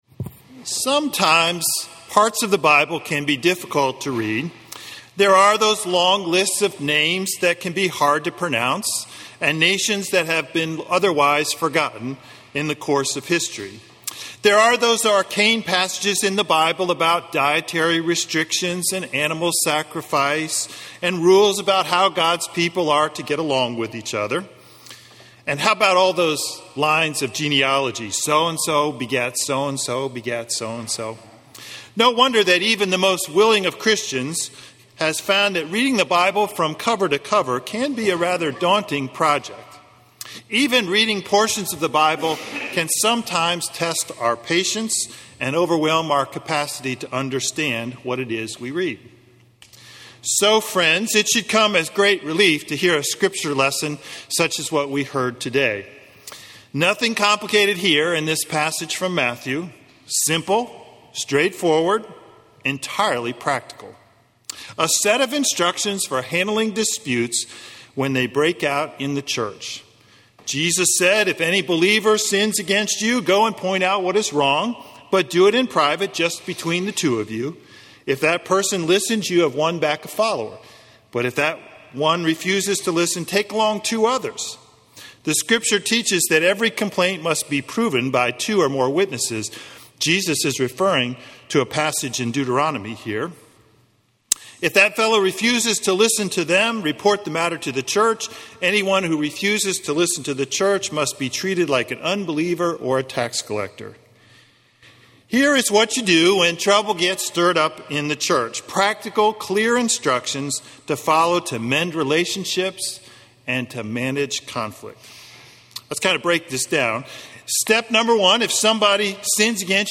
Passage: Ephesians 4: 1-6 and Matthew 18: 15-20 Service Type: Sunday Morning Worship